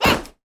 Sfx Player Kick Sound Effect
sfx-player-kick.mp3